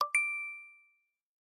Categoría Notificaciones